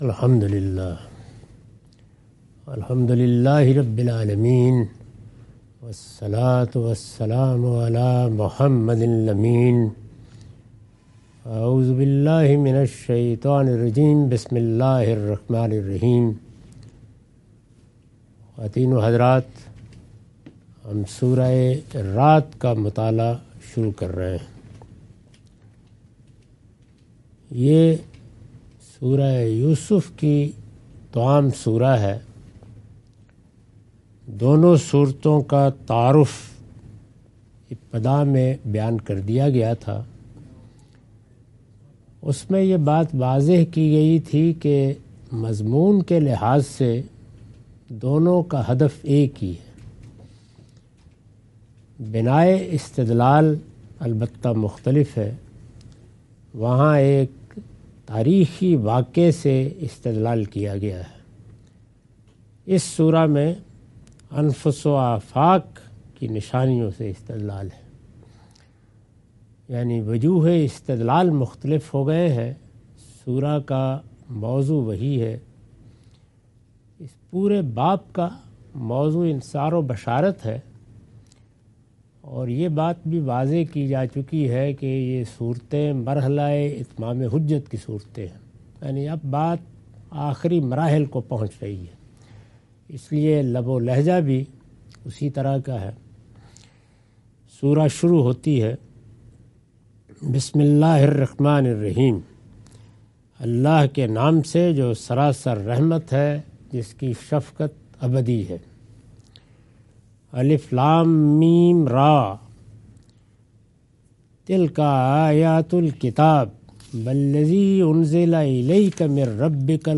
Surah Ar-Rad - A lecture of Tafseer-ul-Quran – Al-Bayan by Javed Ahmad Ghamidi. Commentary and explanation of verses 01-03.